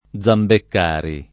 [ +z ambekk # ri ]